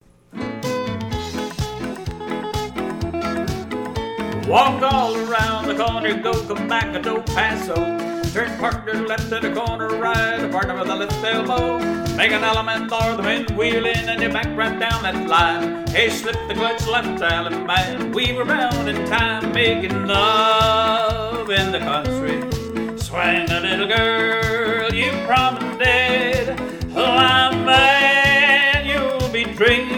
Instrumental
Vocal